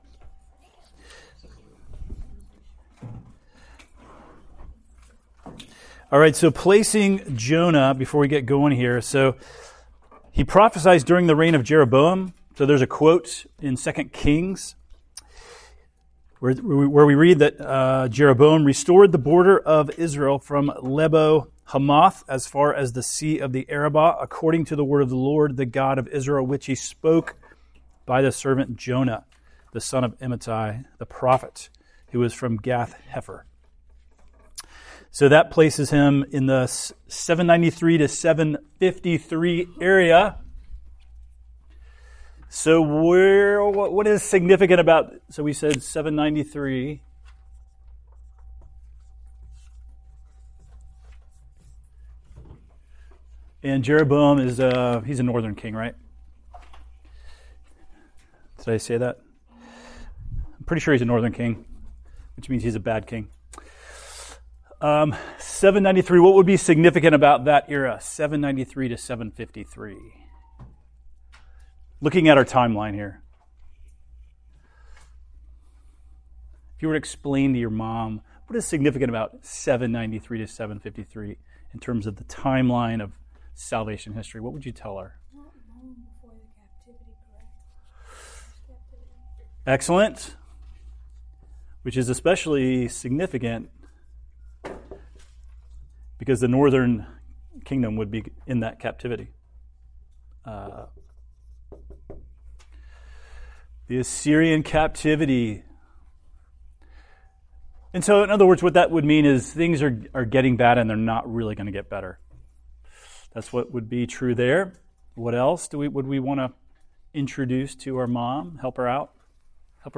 This podcast contains all of the sermons and recorded teachings at Cornerstone Fellowship Church, a reformed charismatic church in Apex, North Carolina.
Old-Testament-Introduction-Class-16-Jonah.mp3